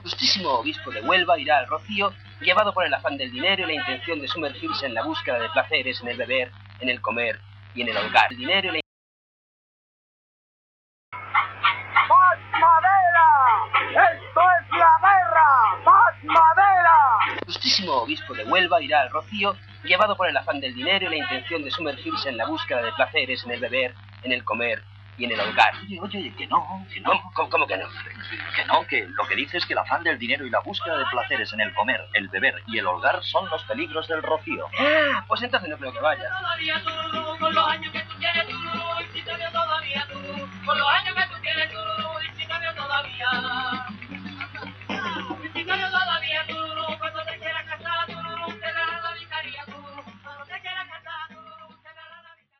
Paròdia sobre la notícia "El obispo de Huelva censura los placeres "en el comer, en el beber y en el holgar" del Rocío"
Entreteniment